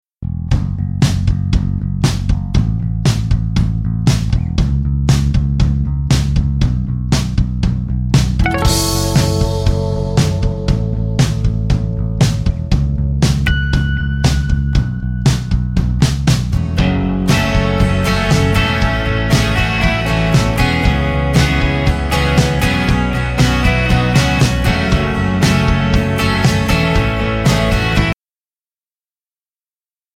Alternative,Folk,Rock